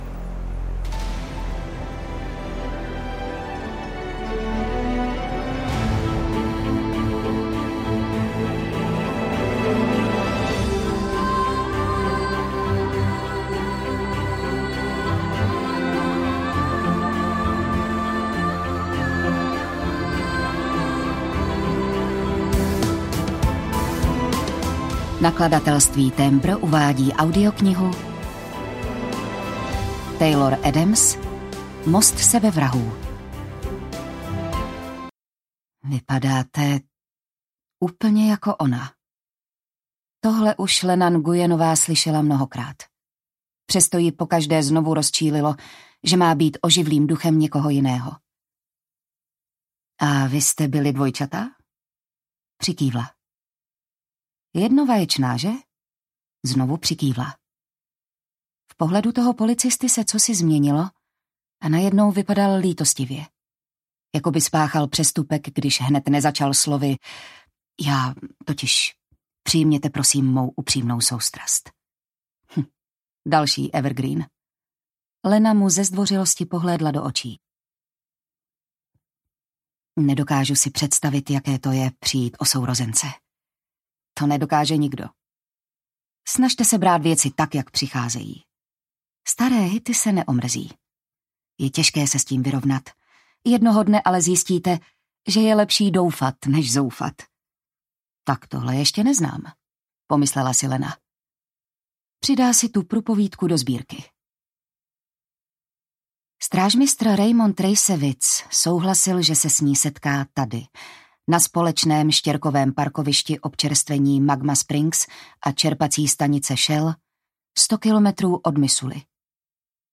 Most sebevrahů audiokniha
Ukázka z knihy
most-sebevrahu-audiokniha